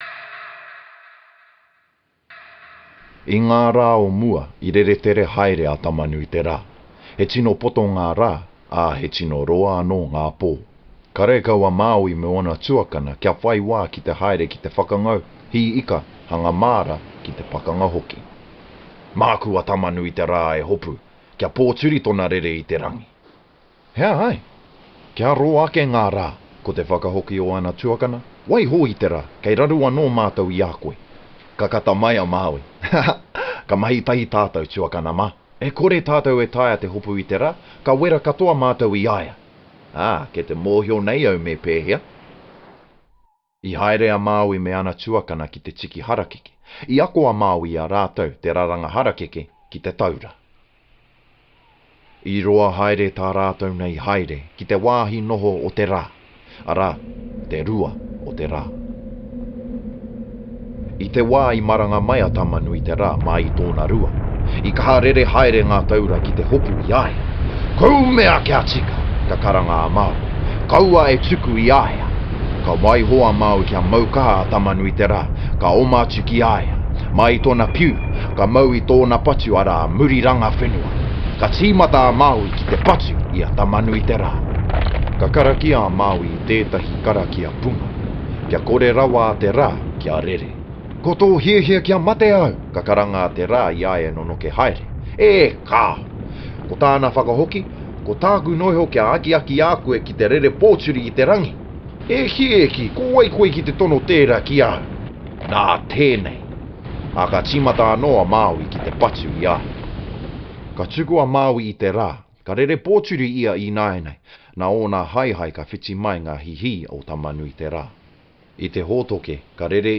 Accents: English | New Zealand international english te reo maori
VOICEOVER GENRE documentary NARRATION
authoritative gravitas
trustworthy warm well spoken HOME STUDIO basic home studio